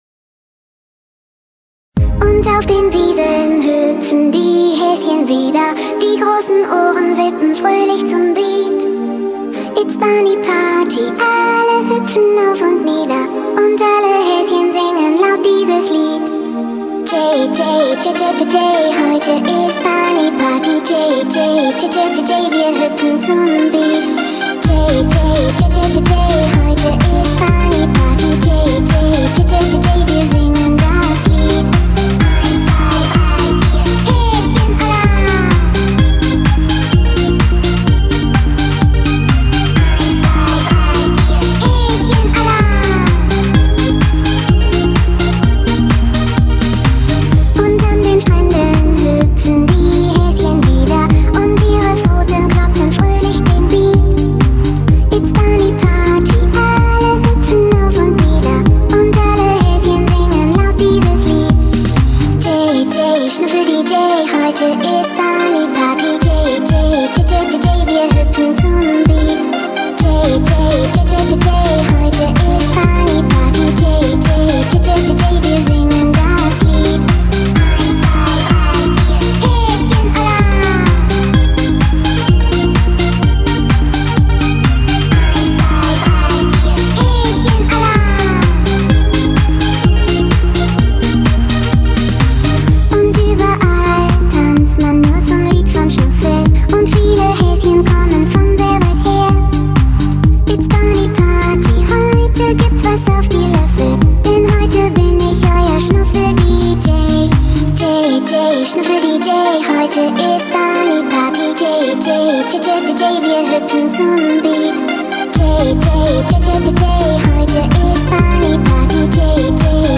[16/8/2008]酷酷的童声慢摇 激动社区，陪你一起慢慢变老！